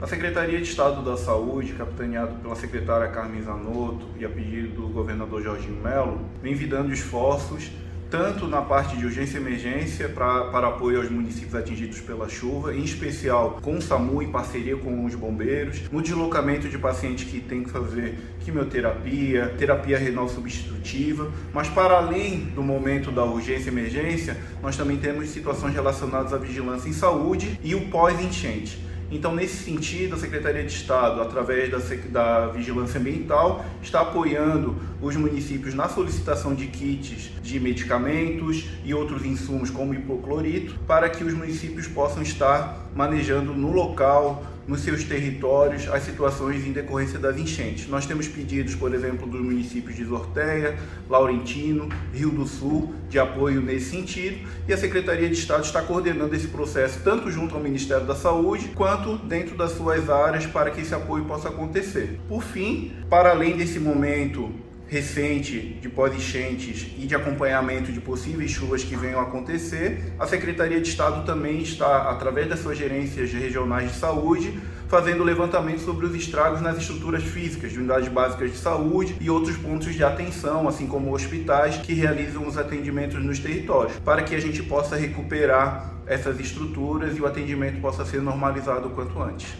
O secretário adjunto de Estado da Saúde, Diogo Demarchi, explica que o trabalho está sendo tanto na parte de urgência e emergência, quanto na questão de vigilância em saúde e o pós-enchente: